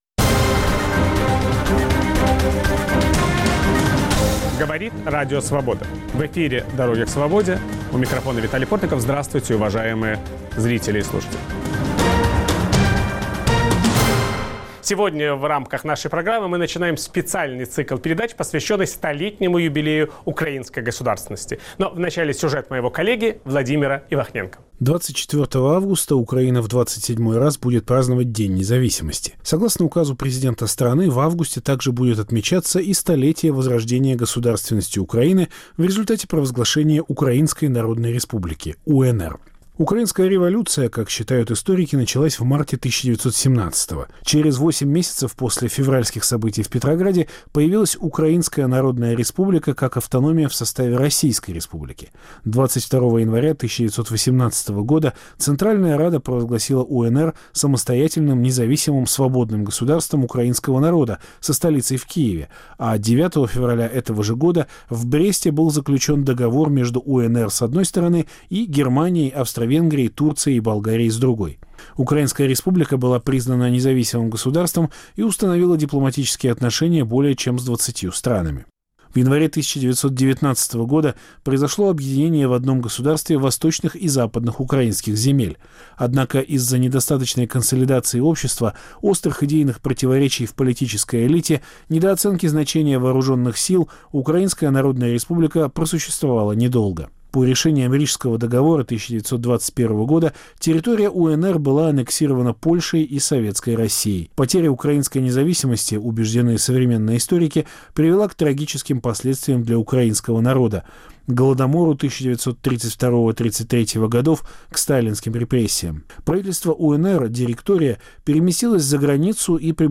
Какое влияние оказало появление Украинской народной республики на провозглашение украинской независимости в 1991 году? Виталий Портников беседует с историком